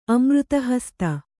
♪ amřta hasta